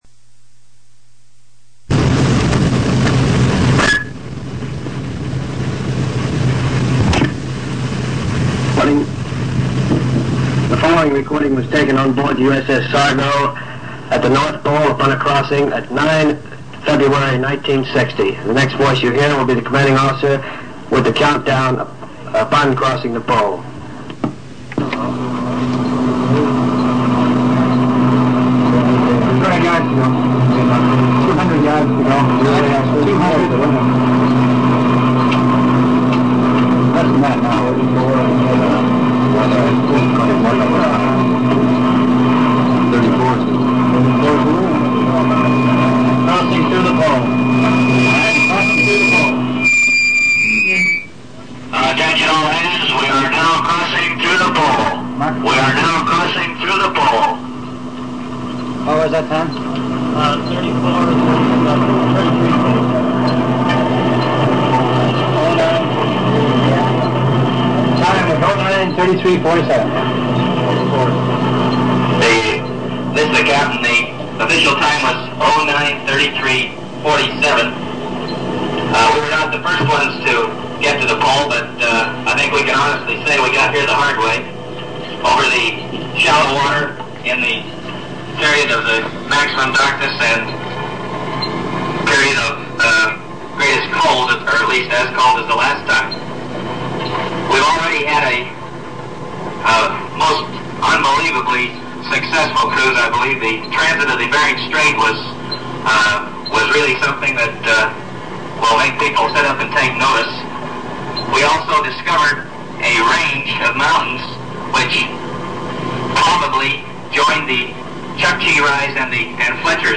The audio recording then captures the activity in the Control Room as the ship surfaces at the North Pole for the very first time.